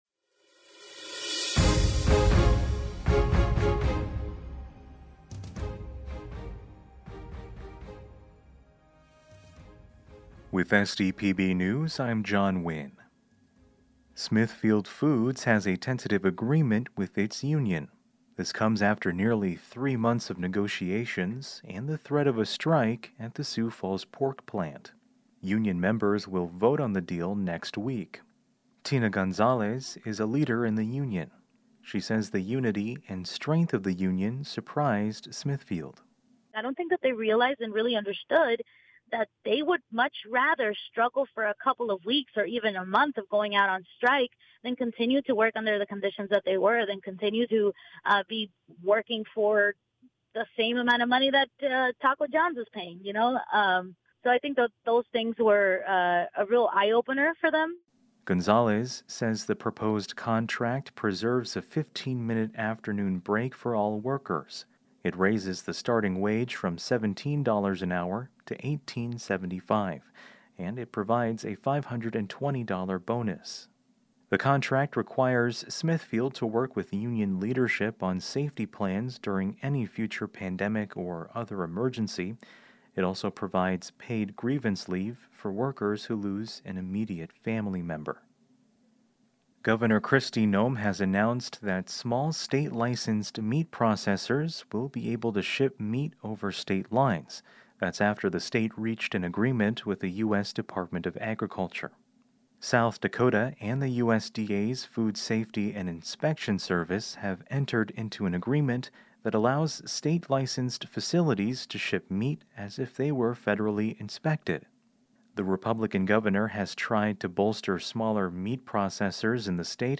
We then compile those stories into one neatly formatted daily podcast so that you can stay informed.